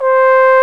Index of /90_sSampleCDs/Roland LCDP12 Solo Brass/BRS_Trombone/BRS_Tenor Bone 2